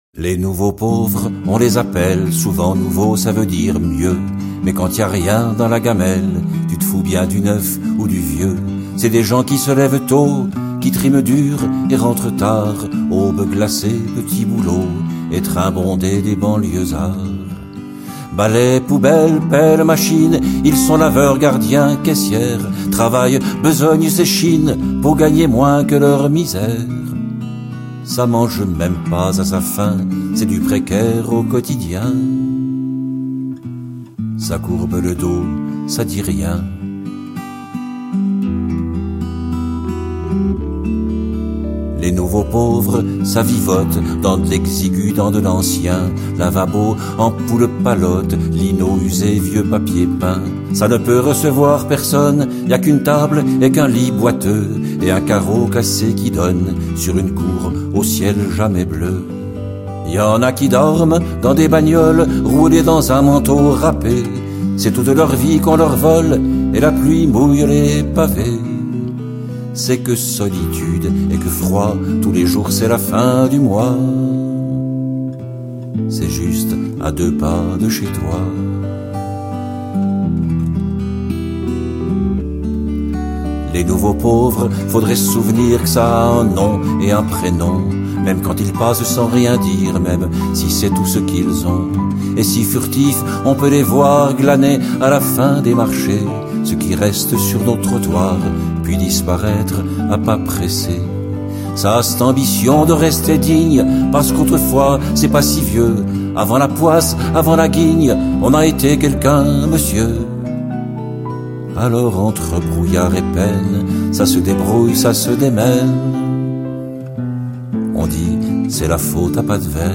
claviers, instrument midi
Guitares
Accordéon, bandonéon
Contrebasse, basse électrique
Batterie, percussions